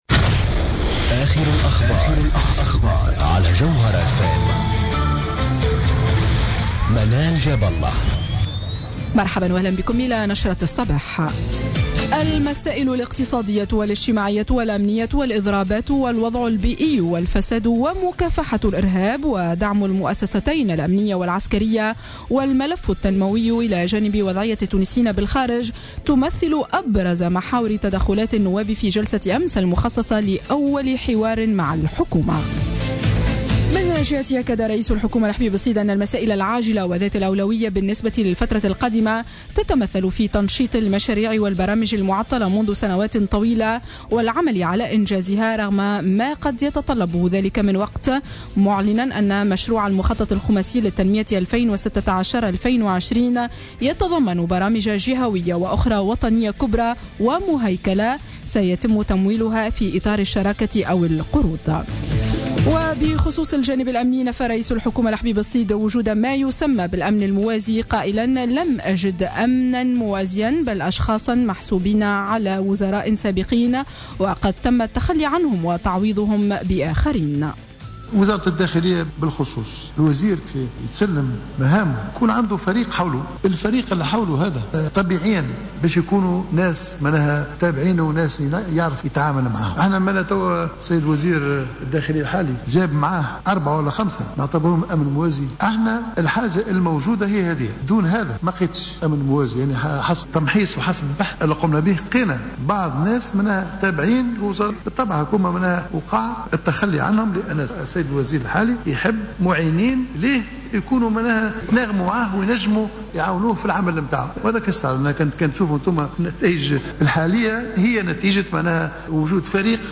نشرة أخبار السابعة صباحا ليوم السبت 4 أفريل 2015